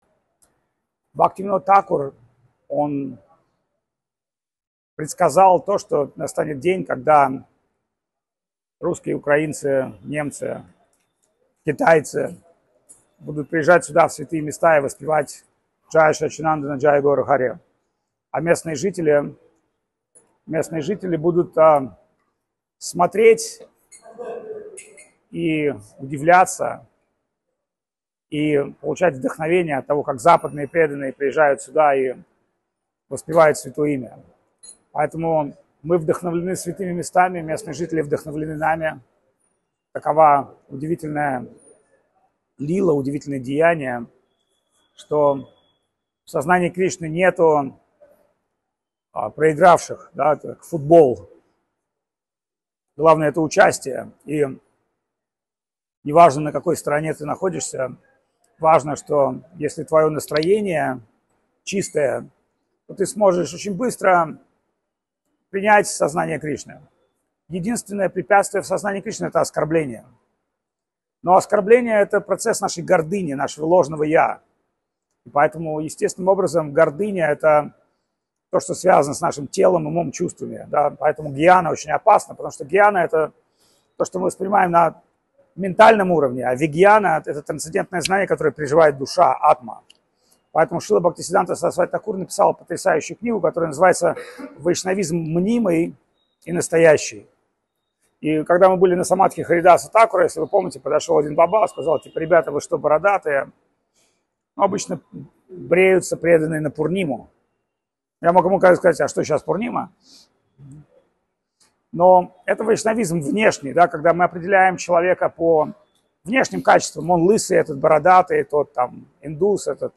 «Подлинное сознание Кришны». В доме Бхактивинода Тхакура.
Джаганнатха Пури, Индия
KHari_katkha_v_dome_Bkhaktivinoda_Tkhakura_Dzhaganatkha_Puri_chast2.MP3